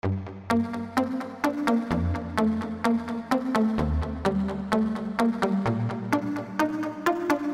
描述：派克循环128bpm。享受
Tag: 128 bpm Dance Loops Synth Loops 1.26 MB wav Key : Unknown